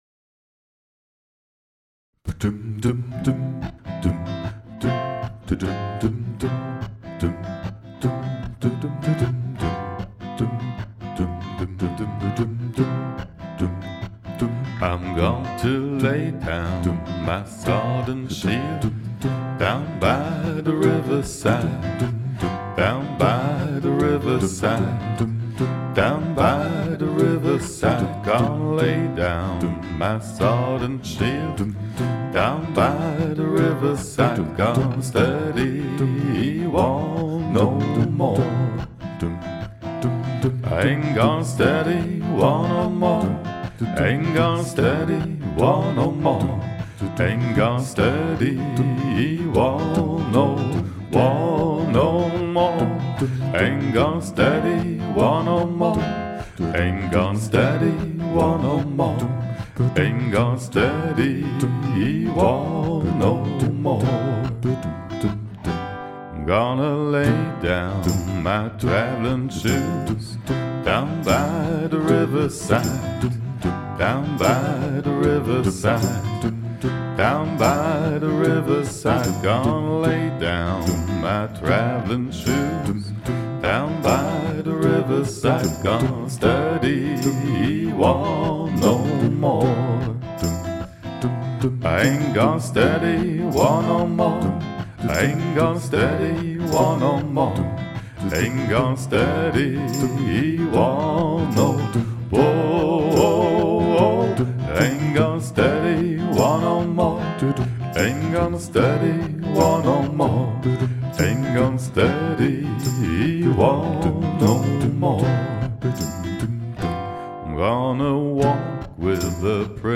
demo für livemugge
die bassstimme läuft live übern looper. die harmonien sind hier eingesungen, funktionieren live über harmonizer. der schellenkranz is am fuß.